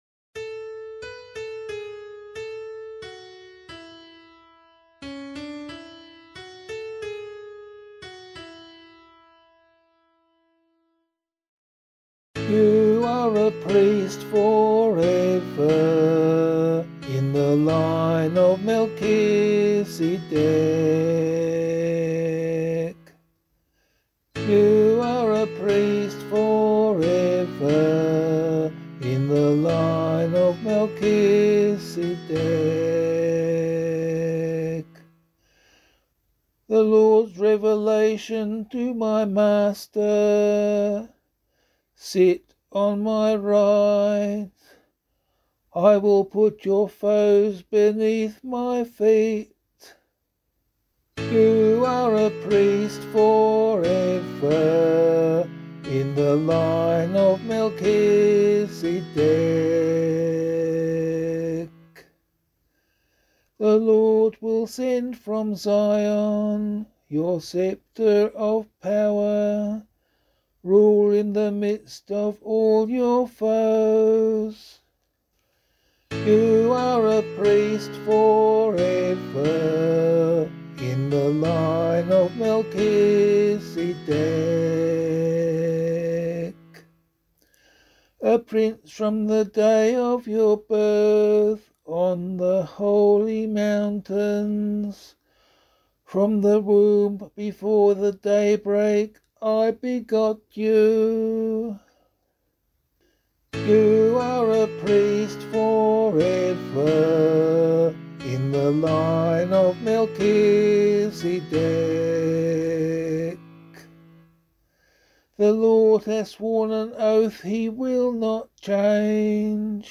034 Corpus Christi Psalm C [LiturgyShare 8 - Oz] - vocal.mp3